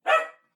Woof.mp3